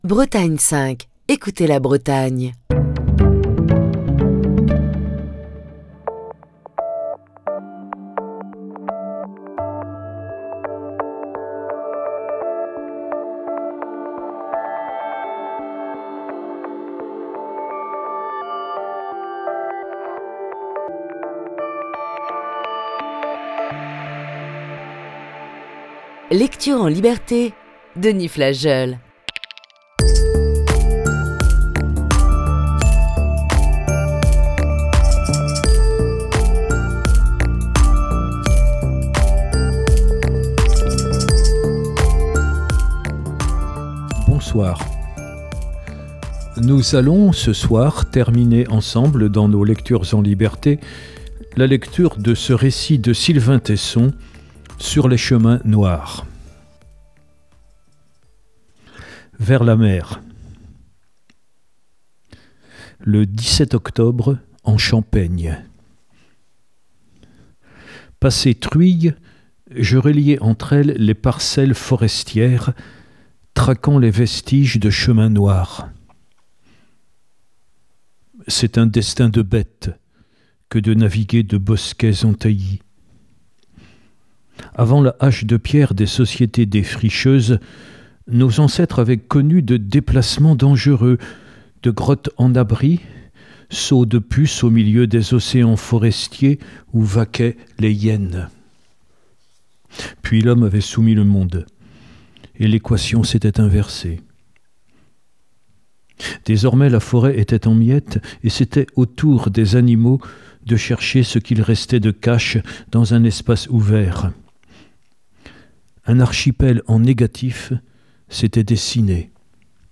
Voici ce soir la dernière partie de ce récit.